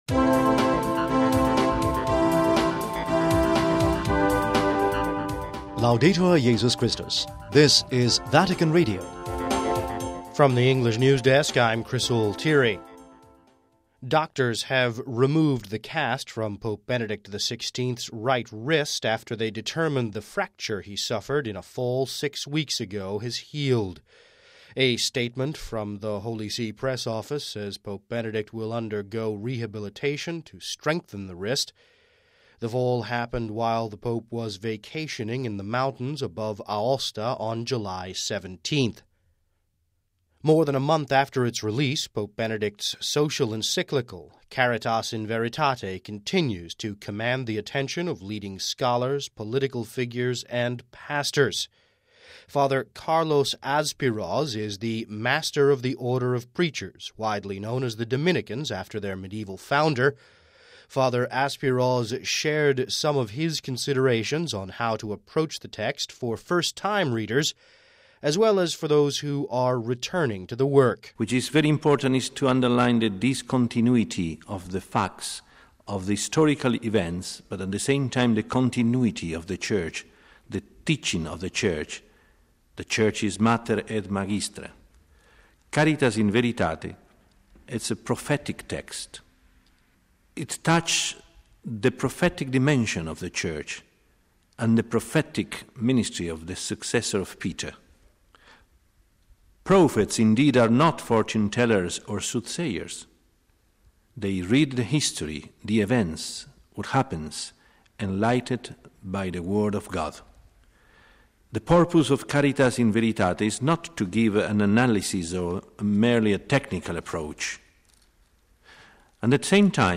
(22 Ago 09 - RV) More than a month after its release, Pope Benedict XVI's Encyclical Letter, Caritas in veritate , continues to draw the attention of leading scholars, political figures and pastors. We have this report, which opens with news of the Friday removal of Pope Benedict XVI's wrist cast...